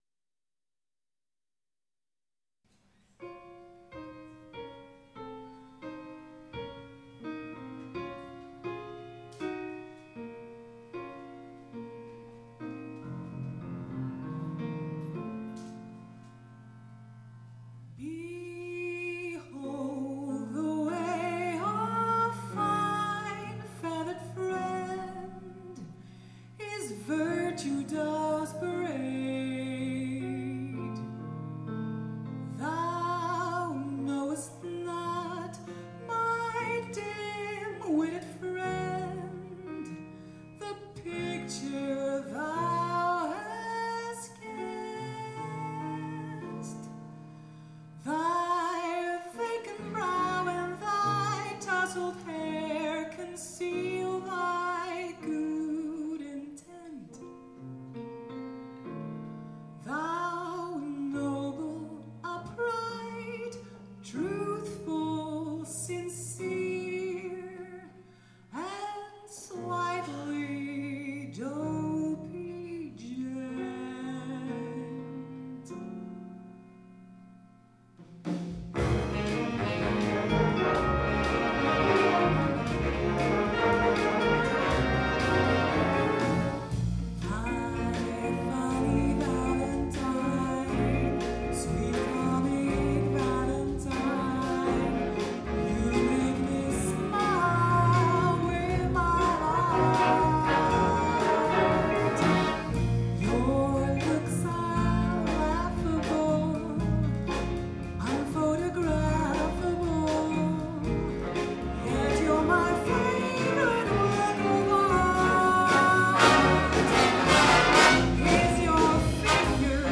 · Genre (Stil): Big Band